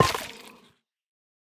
Minecraft Version Minecraft Version snapshot Latest Release | Latest Snapshot snapshot / assets / minecraft / sounds / block / sculk_catalyst / break1.ogg Compare With Compare With Latest Release | Latest Snapshot
break1.ogg